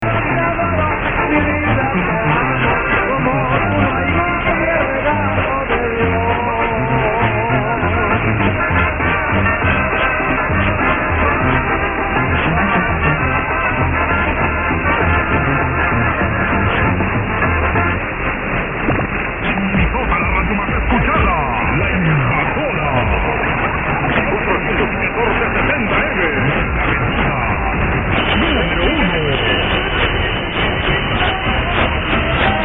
A FEW AUDIO CLIPS OF RECENT RECEPTION: